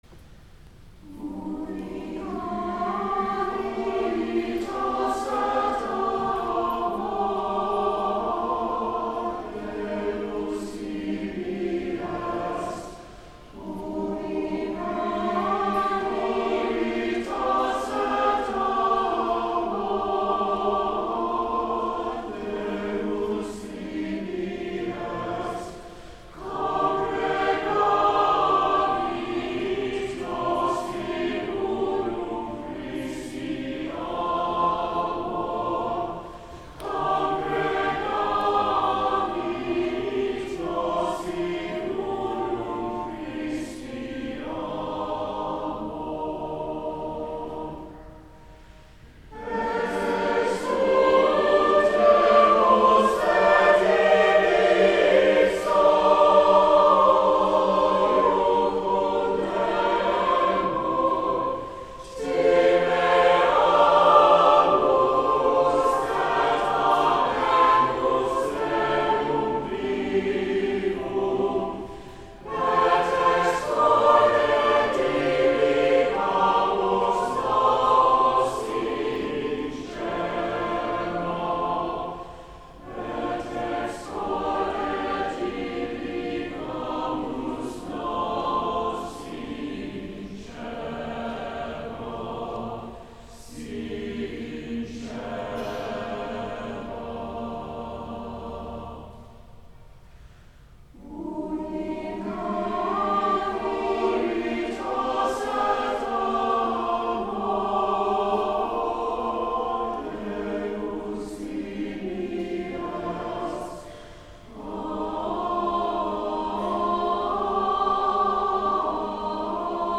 Holy Baptism/Holy Eucharist
Cathedral Choir